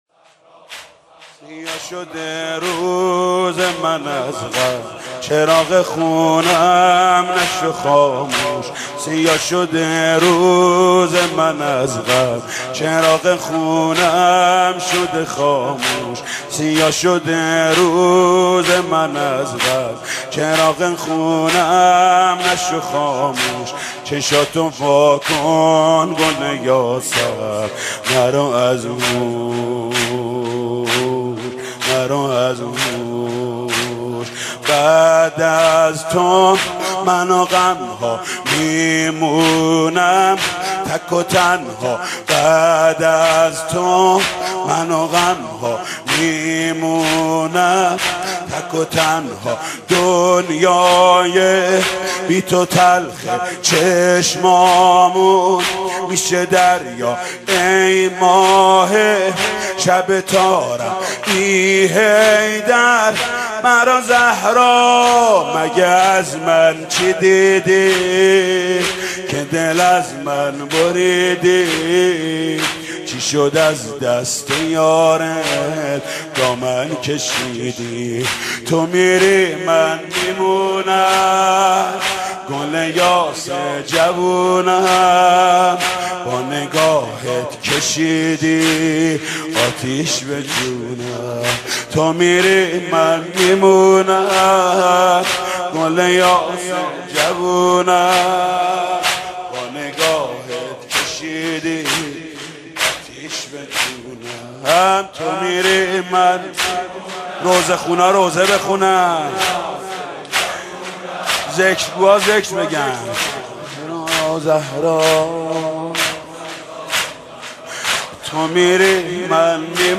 «فاطمیه 1391» زمینه: سیاه شده روز من از غم